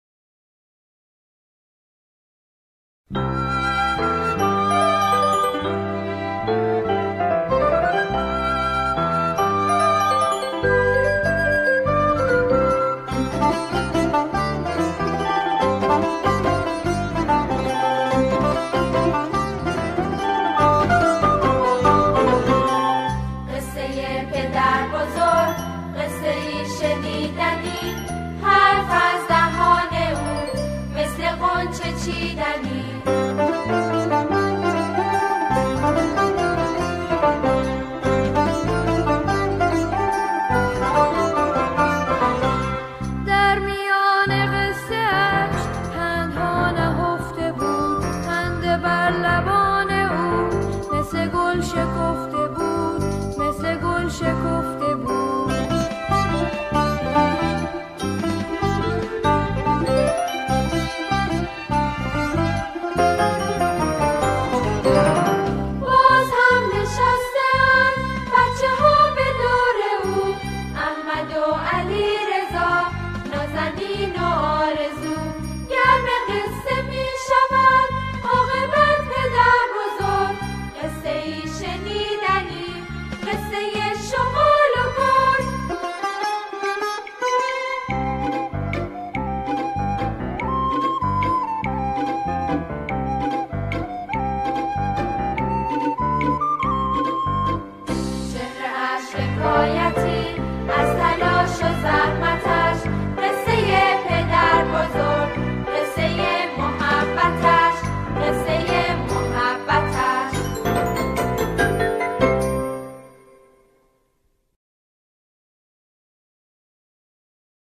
سرود کودکانه
آنها در این قطعه، شعری کودکانه را همخوانی می‌کنند.